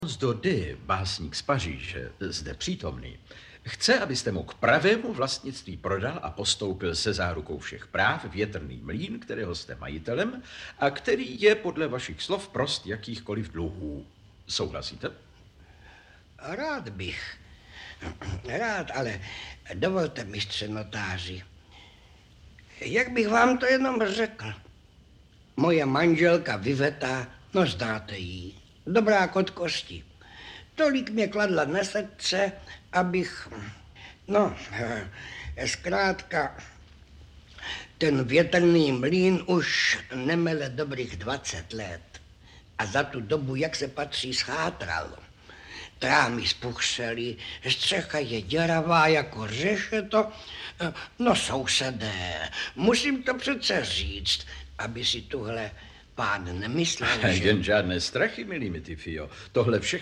Audiobook
Read: František Filipovský